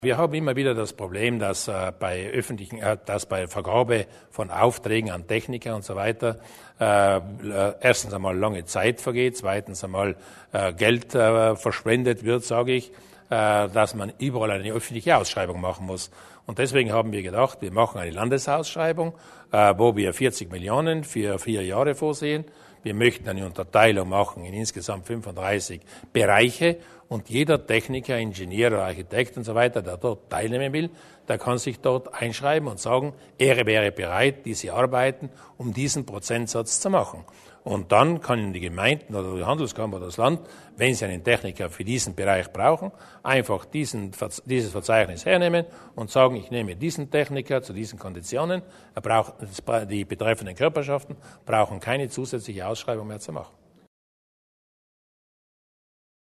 Landeshauptmann Durnwalder zu den Neuheiten bei Beauftragungen